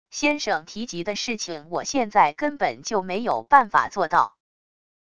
先生提及的事情我现在根本就没有办法做到wav音频生成系统WAV Audio Player